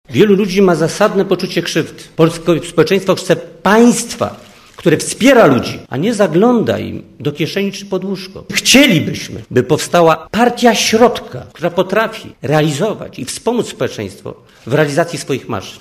* Mówi Władysław Frasyniuk*
Podczas spotkania w Bibliotece Uniwersyteckiej w Warszwie, na które przybyło ponad sto osób, ogłoszono też deklarację ideową nowej formacji.